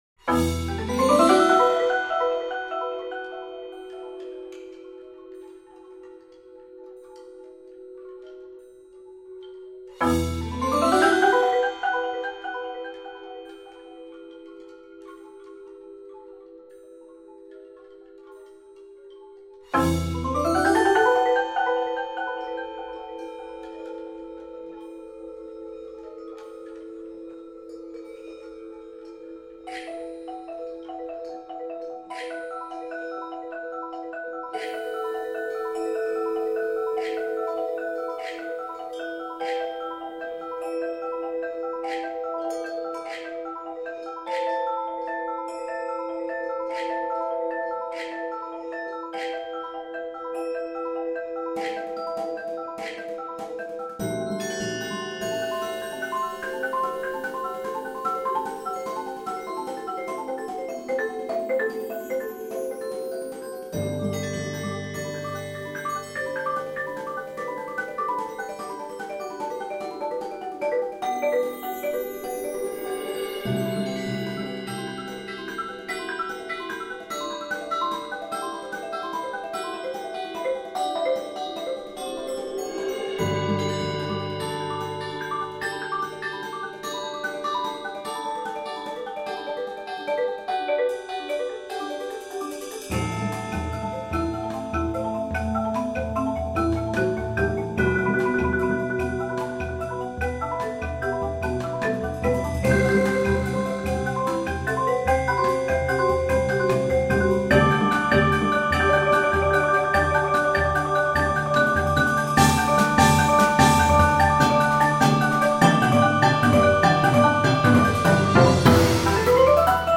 for percussion orchestra
Voicing: 12 Percussion